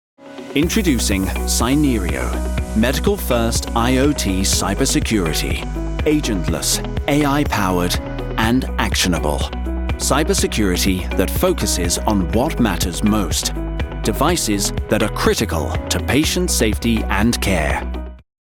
Medical Narration
Recording booth-Session Booth custom-built air-conditioned studio
Mic-Neumann TLM 103